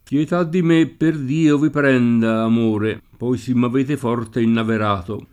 pLet# ddi m%, per d&o, vi pr$nda, am1re, p0i Si mm av%te f0rte innaver#to] (Guittone); sentendosi inaverato, con gran voci comincia a dolersi [Sent$ndoSi inaver#to, koj gran vi kom&n©a a ddol%rSi] (Sacchetti)